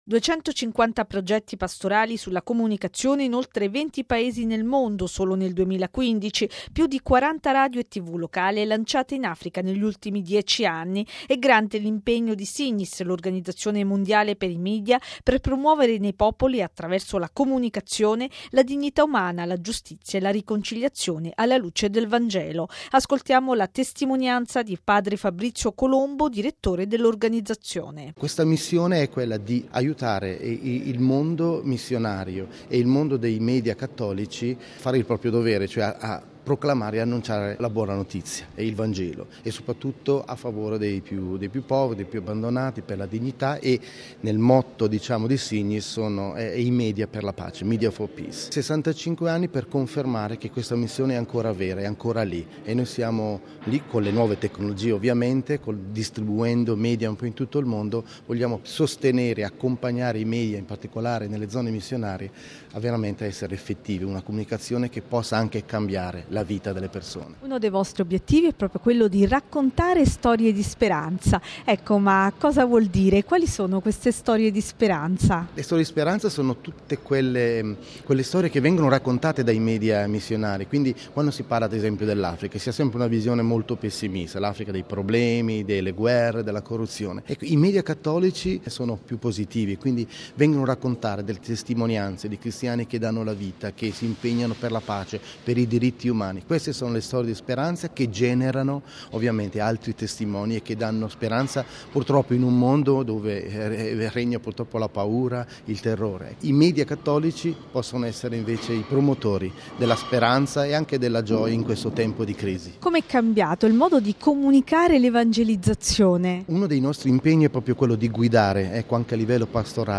Portare la parola di Dio attraverso la comunicazione in oltre 140 Paesi nel mondo, toccando cosi, ogni angolo della terra. E’ questo l’obiettivo di Signis l’Organizzazione Mondiale per i Media e la comunicazione che questa mattina a Roma, a palazzo di San Callisto,  ha celebrato con un incontro i 65 anni di attività, dove ha ripercorso le varie tappe della sua storia e presentato nuove prospettive.